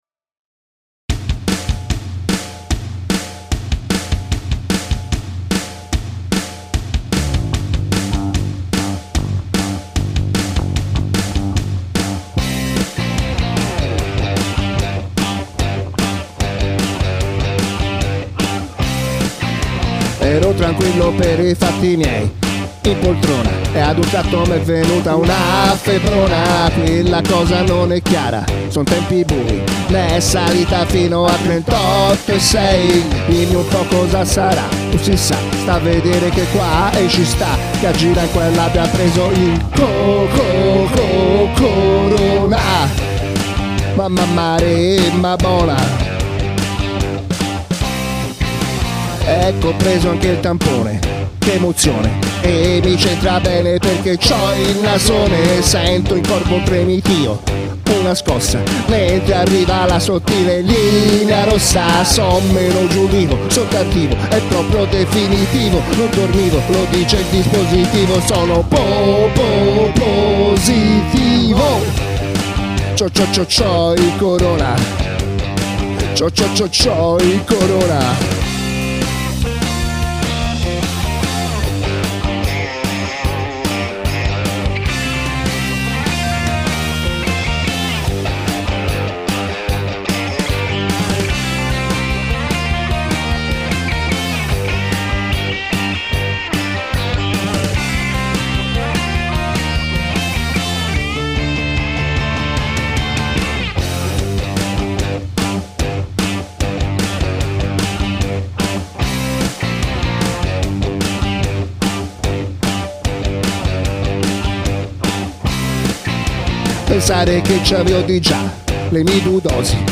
No, non è uno scherzo, ho veramente beccato il coronavirus! Ma siccome è la vita a essere uno scherzo, eccomi a cantarci su!